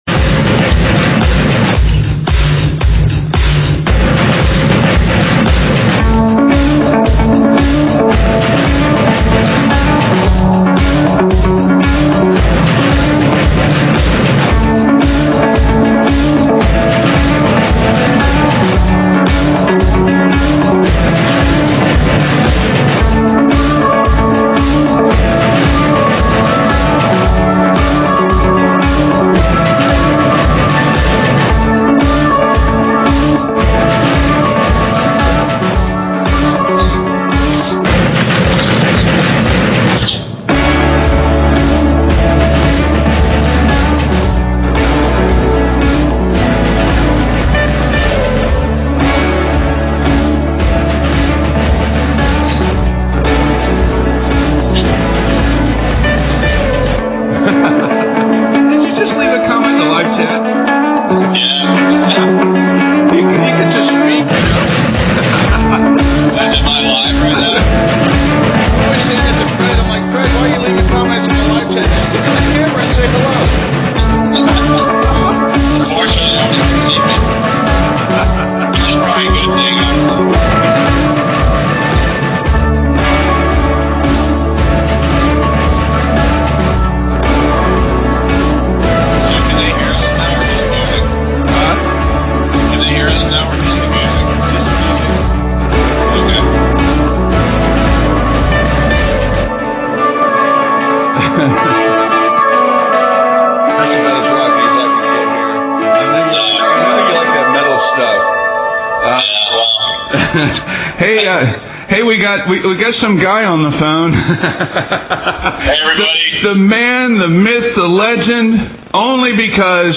Guest Appearances & Interviews